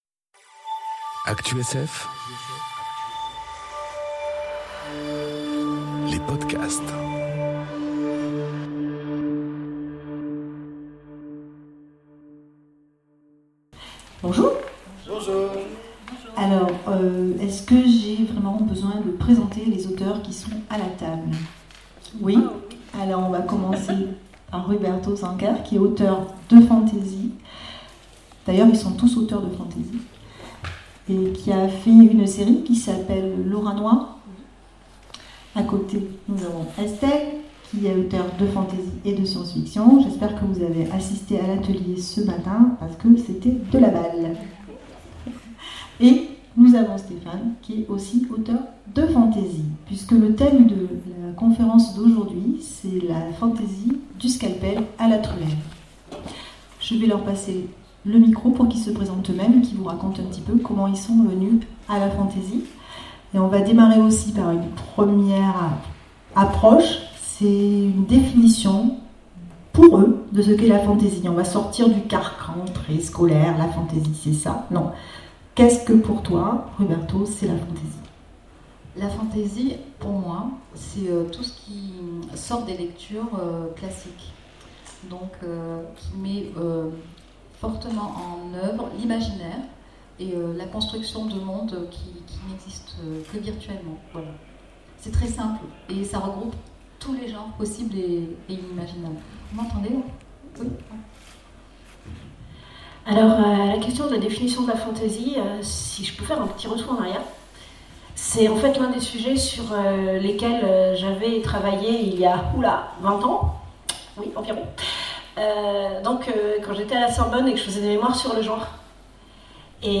Le site ActuSF vient de poster les podcasts des conférences enregistrée à l'occasion des Aventuriales de Ménétrol qui se sont déroulées les 28 et 29 septembre dernier.
La conférence *** Retouvez les vidéos sur la chaîne Youtube des Aventuriales